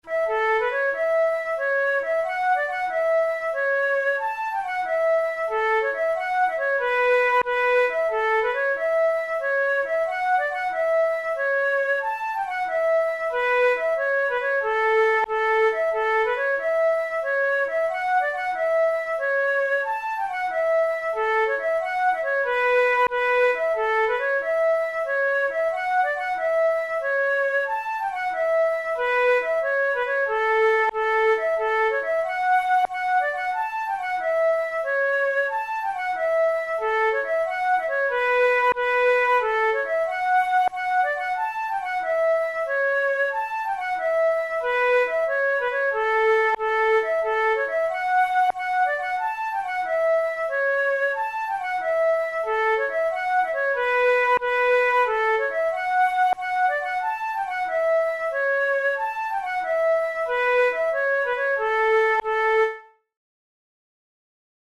Traditional Scottish pipe tune
Categories: Celtic Music Traditional/Folk Difficulty: easy